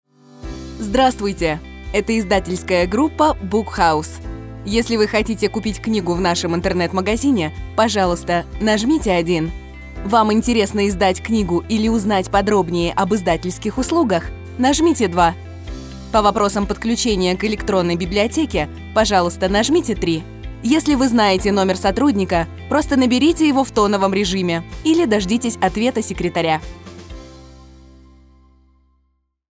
Диктор
IVR